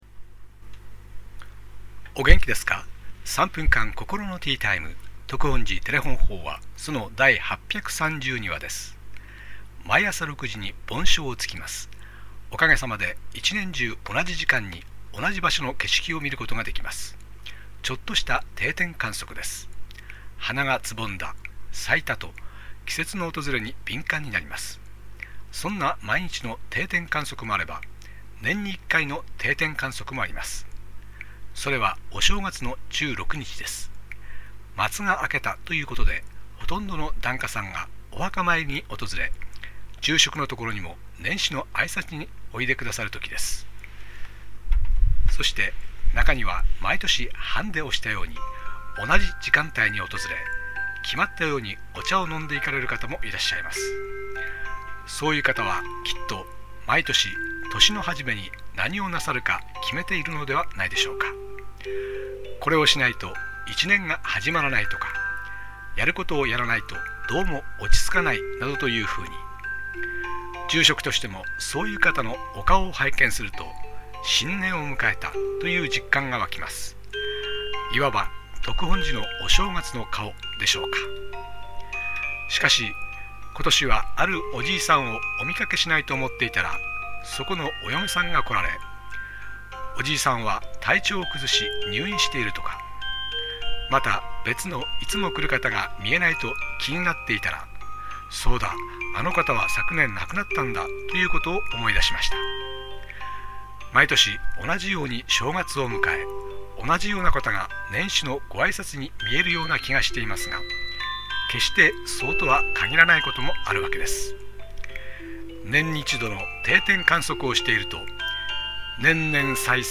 テレホン法話
住職が語る法話を聴くことができます